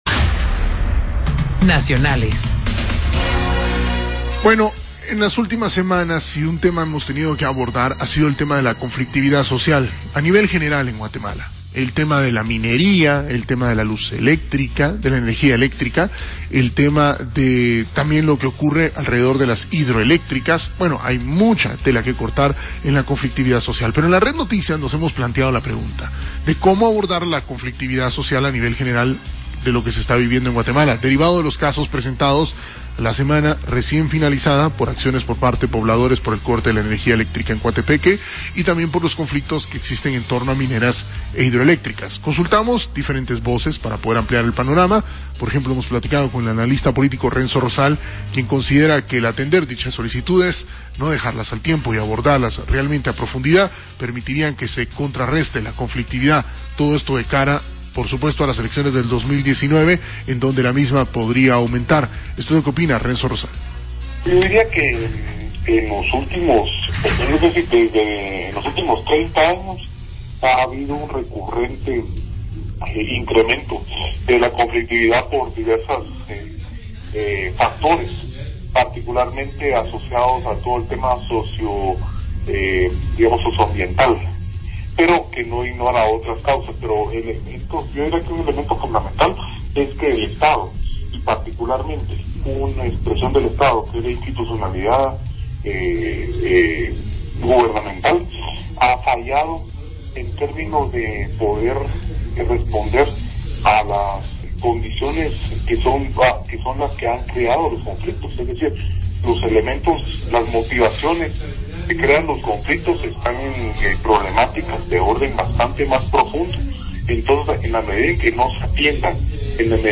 RCN NOTICIAS / LA RED: Análisis. Formas de enfrentar la conflictividad social a nivel general. Corte de energía eléctrica en Coatepeque y conflictos que existen en torno a mineras e hidroeléctricas.